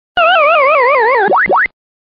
game_over.mp3